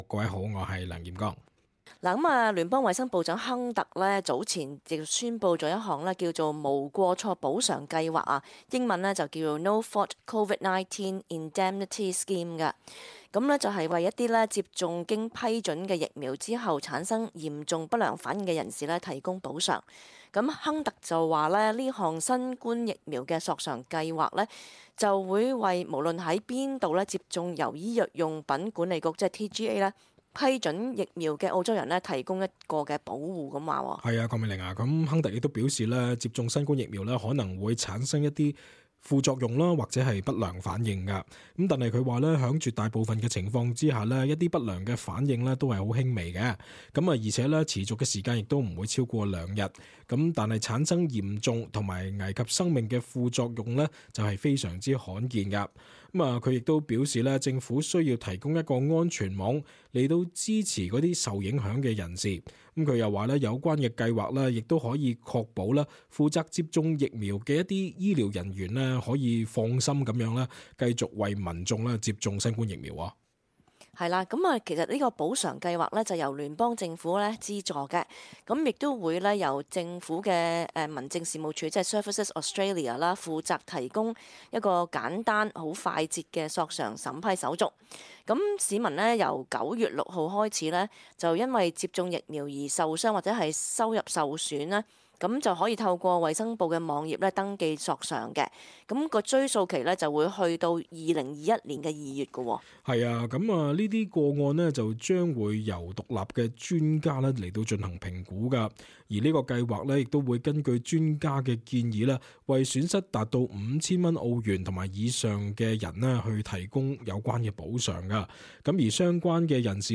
talkback_sept_9_-_final.mp3